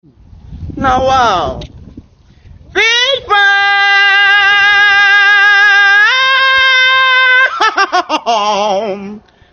Nawa ooo Fish pie | sound effect for comedy
Nawa-o-Fish-pie-comedy-sound-effect.mp3